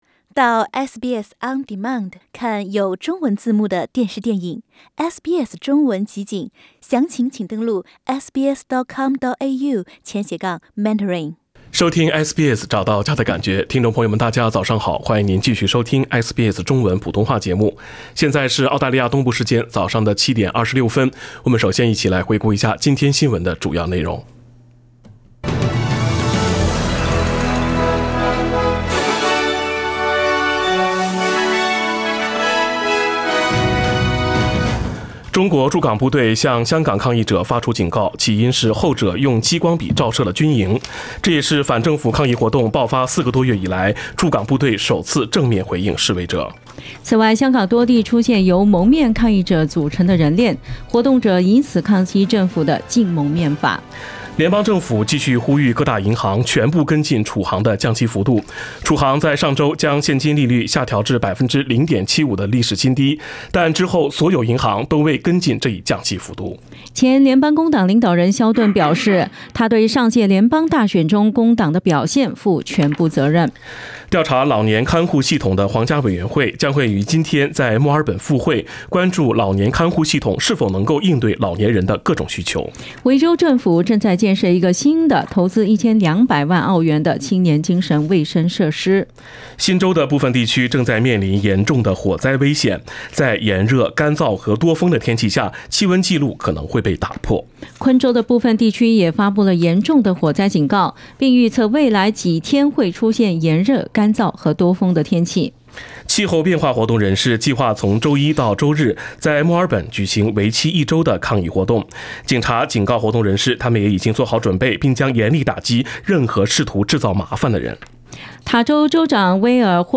SBS 早新闻（10月7日）